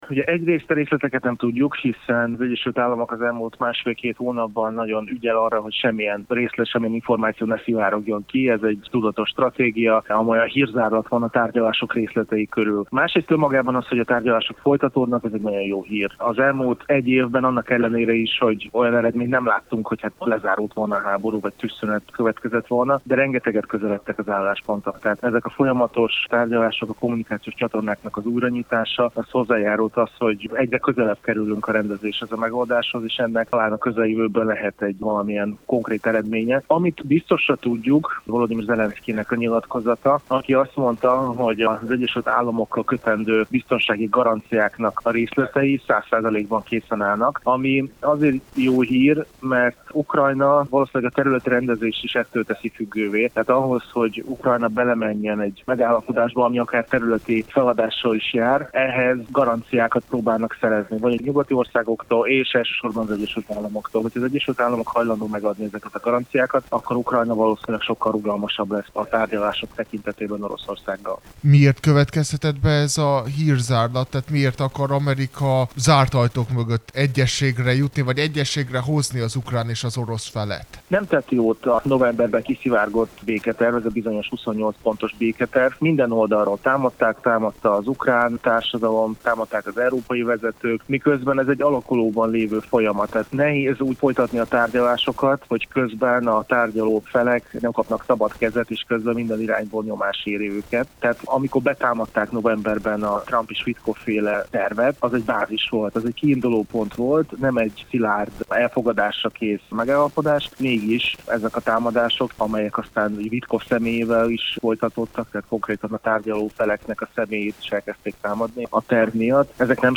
a posztszovjet térség szakértőjével beszélget